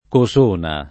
[ ko S1 na ]